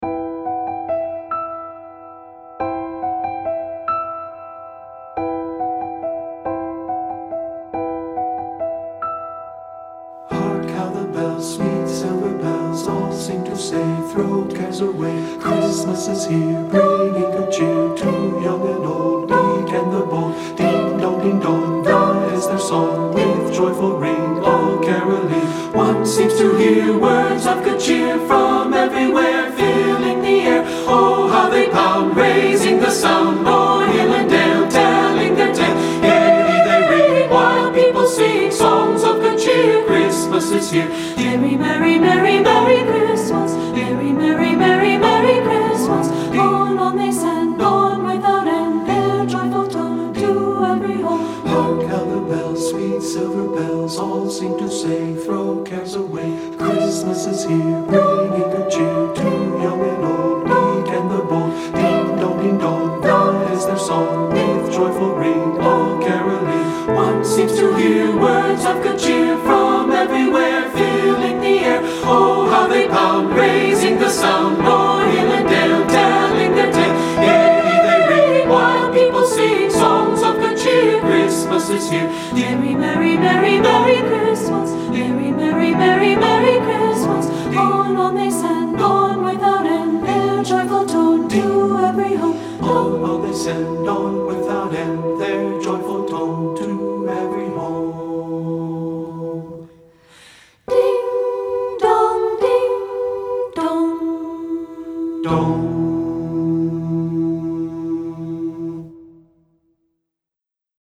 • Voice 1 (Part )
• Voice 2 (Part )
• Alto
• Piano
Studio Recording
Ensemble: Unison and Two-Part Chorus
Accompanied: Accompanied Chorus